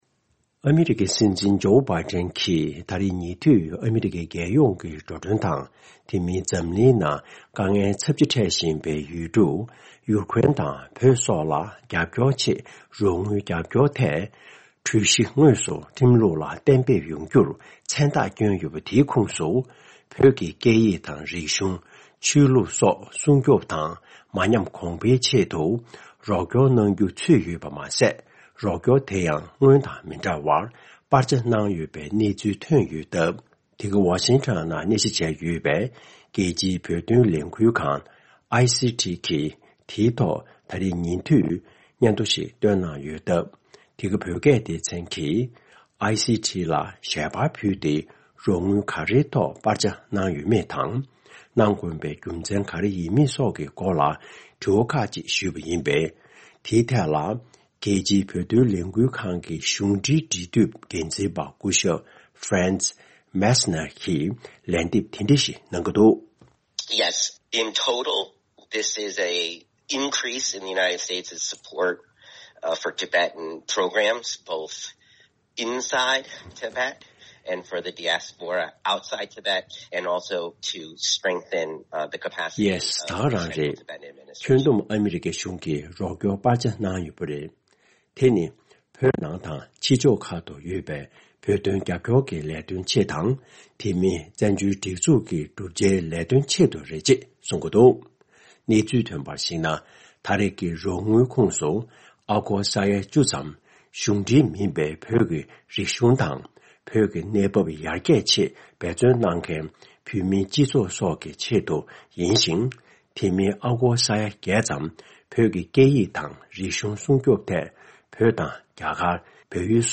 འབྲེལ་ཡོད་མི་སྣར་བཀའ་དྲི་ཞུས་ཏེ་ཕྱོགས་བསྒྲིགས་གནང་བའི་གནས་ཚུལ་དེ་གསན་རོགས་གནང་།